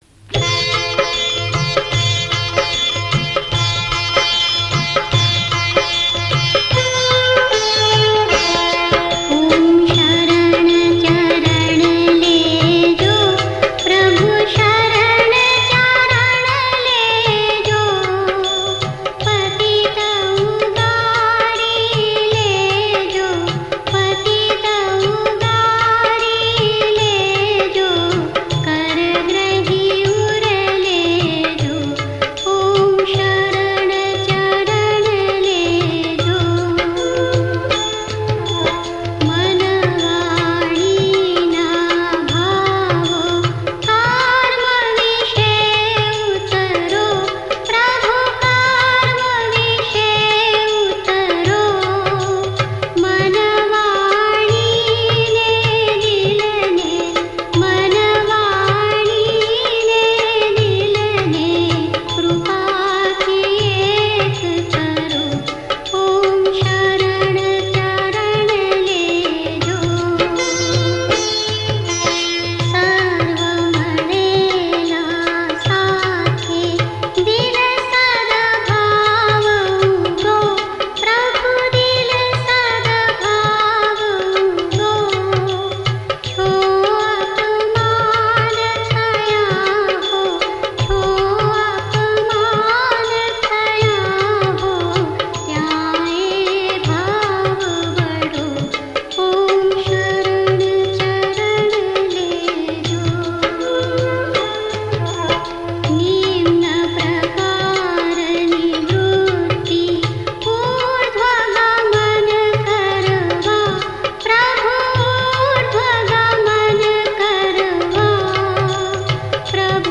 aarti.mp3